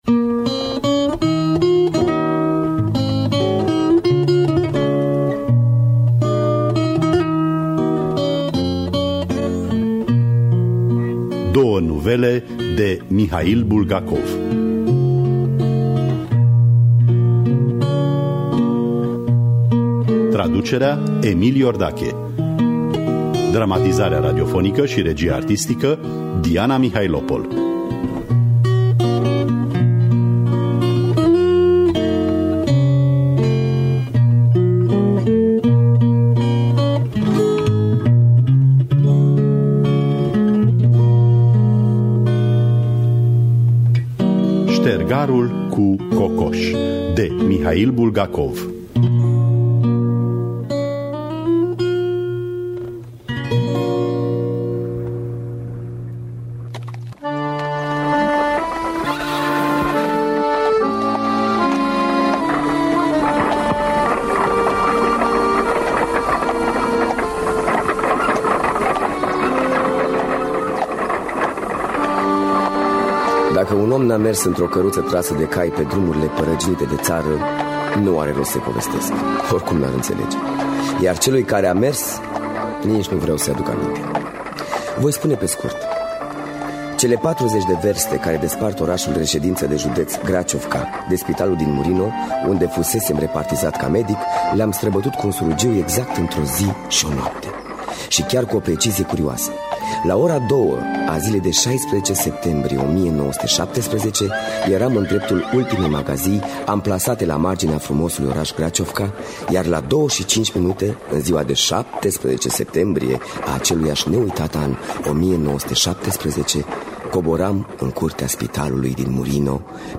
Dramatizarea radiofonică şi regia artistică